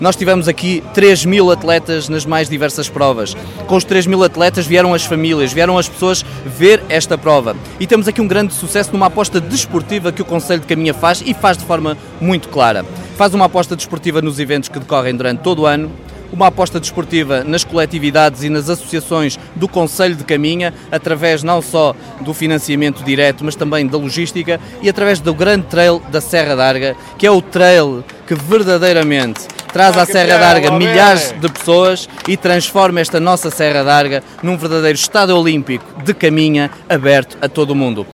O autarca de Caminha fez também um balanço positivo do evento que contou com a participação de mais de 3 mil atletas.